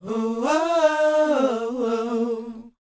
WHOA A#C.wav